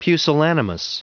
Prononciation du mot pusillanimous en anglais (fichier audio)
pusillanimous.wav